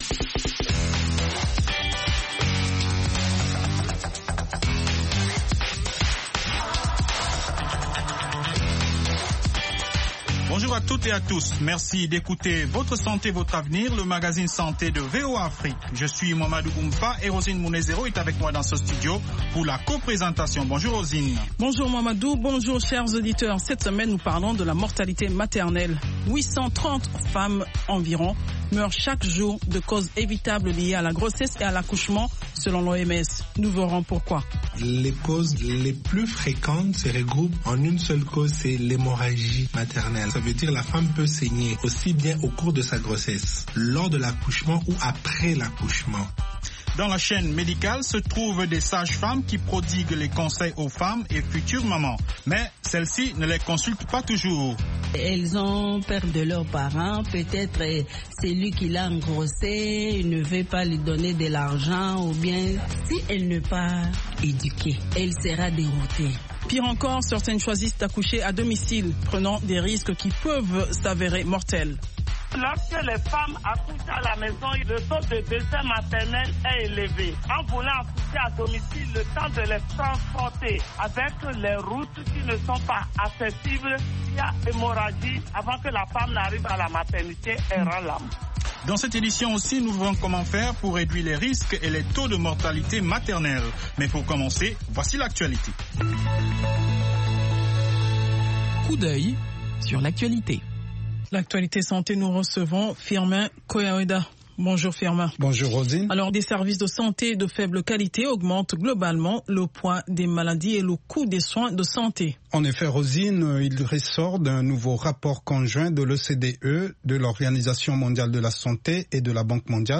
5 Min Newscast